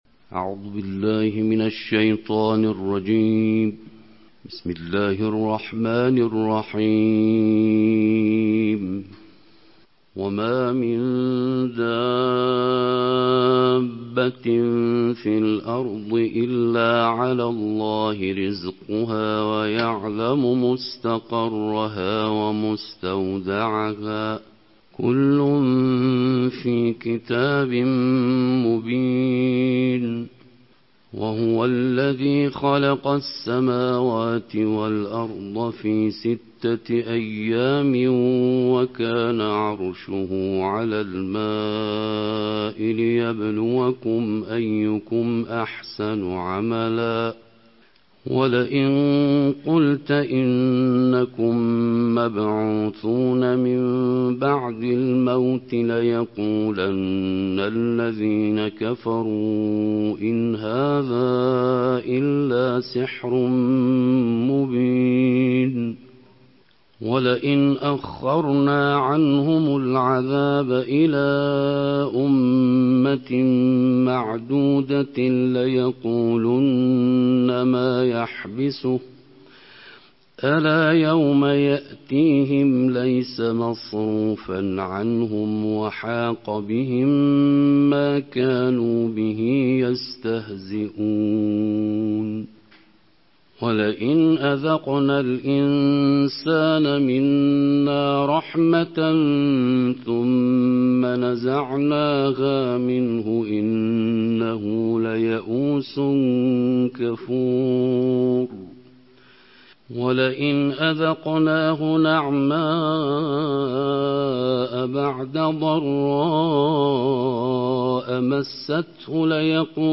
Beynəlxalq qarilərin səsi ilə Quranın on ikinci cüzünün qiraəti